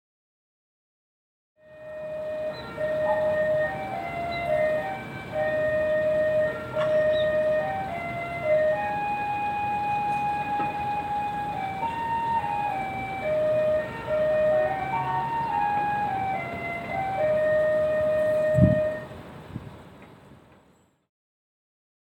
La musiquita del distribuidor de garrafas.